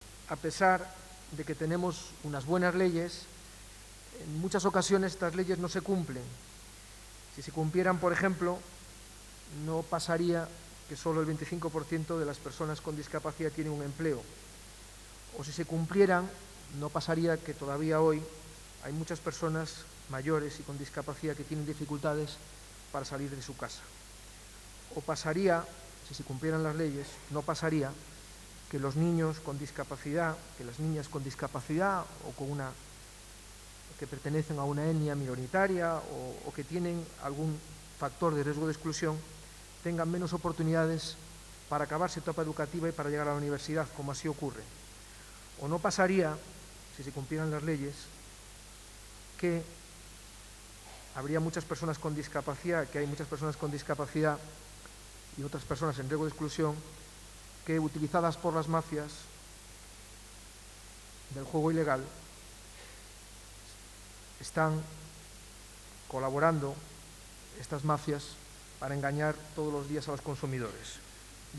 al término de la entrega de galardones en un abarrotado salón de actos del CDC.
En su intervención